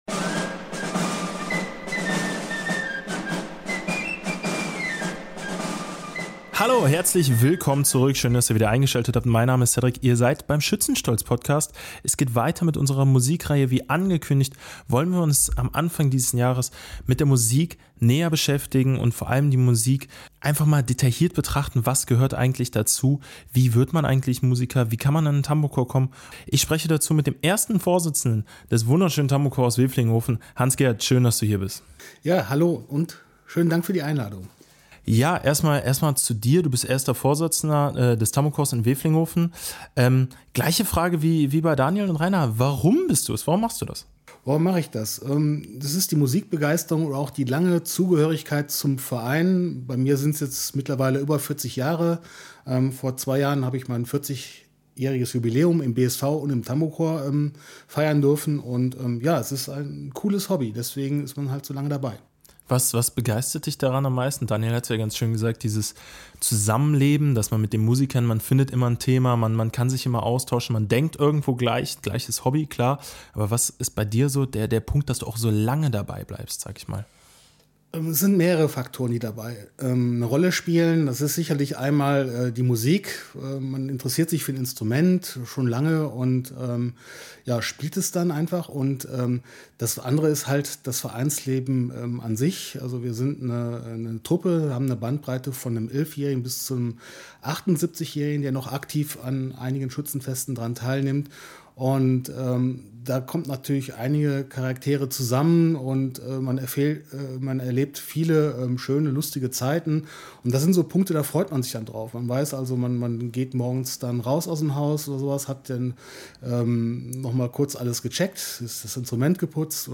Er berichtet, wie junge Musiker an die Trommel, Querflöte oder Lyra herangeführt werden, welche Herausforderungen die Nachwuchsgewinnung mit sich bringt und warum die Jugendarbeit essenziell für den Erhalt dieser musikalischen Tradition ist. Schützenstolz ist der Podcast des Bürger-Schützen-Vereins 1924 Wevelinghoven e.V. Wir bringen euch spannende Geschichten, interessante Gespräche und exklusive Einblicke rund um das Vereinsleben und die Traditionen unseres Schützenvereins.